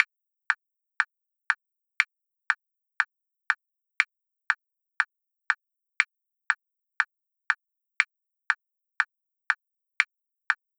Comfort noise
Something in the -60dB range.